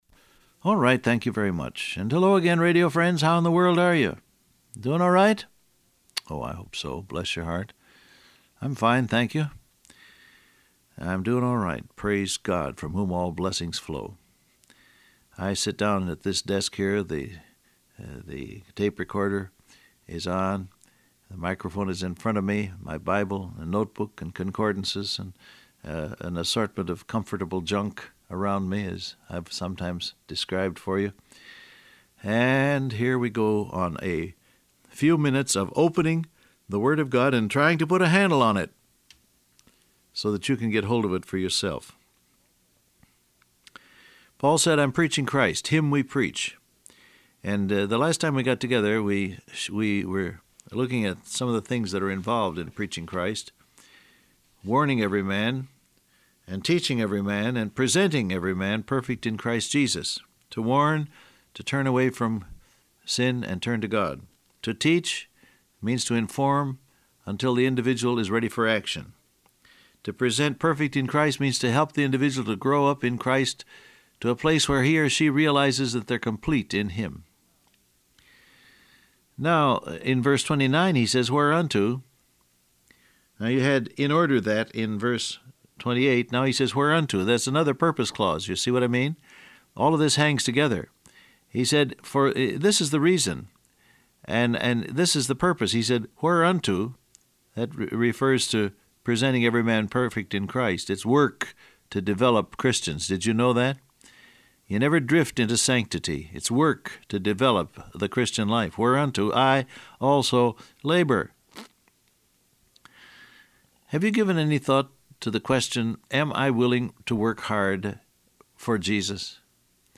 I sit down at this desk here, the tape recorder is on, the microphone is in front of me, my Bible and notebook and concordances and an assortment of comfortable junk around me as I’ve sometimes described for you.